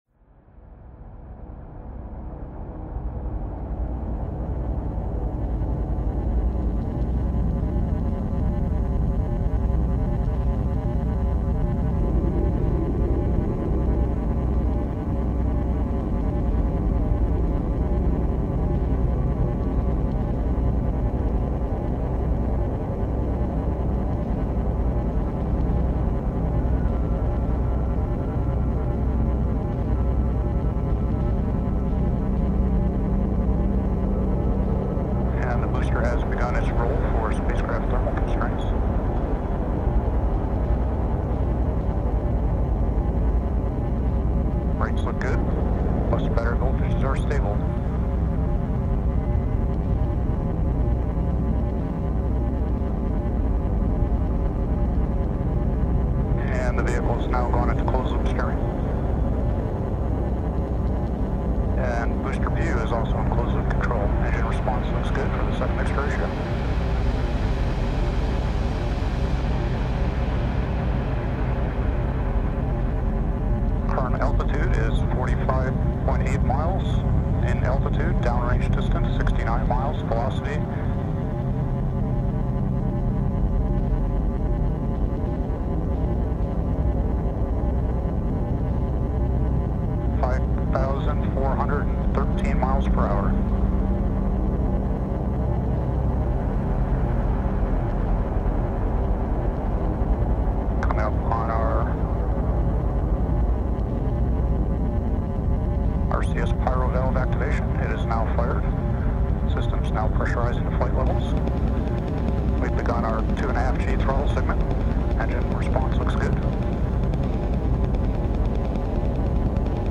Electronic, Progressive Rock